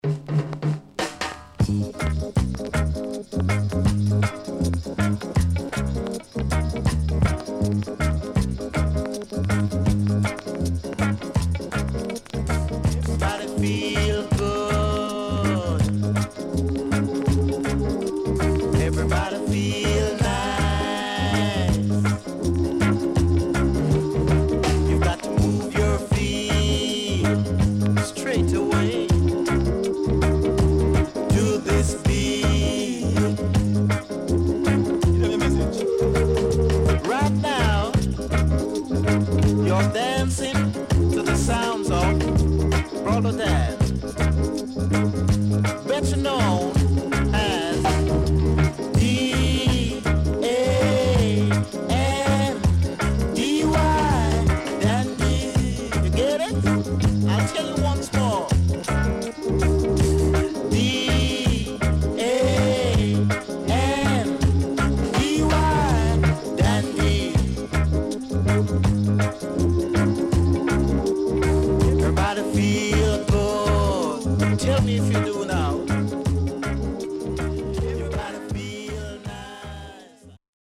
HOME > REGGAE / ROOTS  >  EARLY REGGAE
Rare.Mellow Early Reggae Vocal.W-Side Good
SIDE B:所々チリノイズがあり、少しプチノイズ入ります。